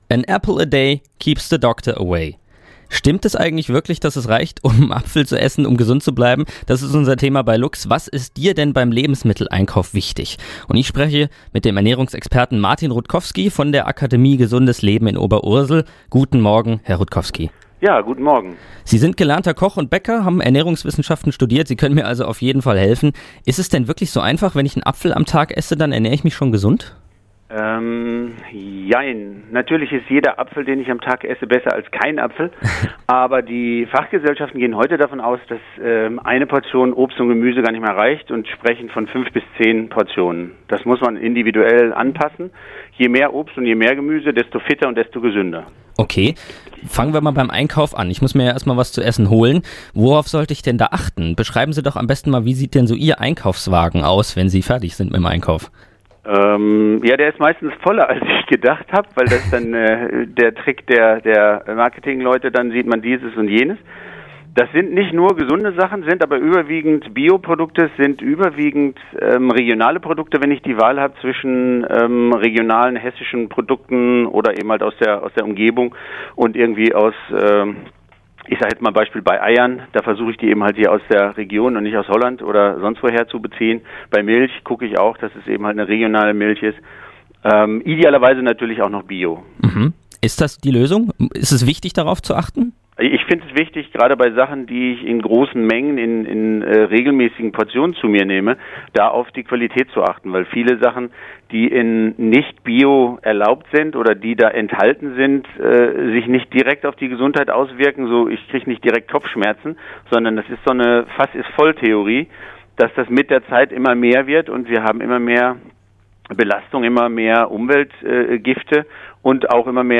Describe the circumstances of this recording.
telefoniert.